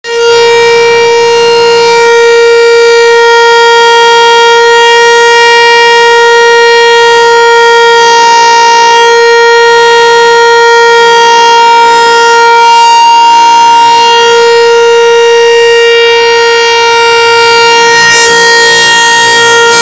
SIRENE ELETRICA ELETROMECANICA
Audio-sirene.mp3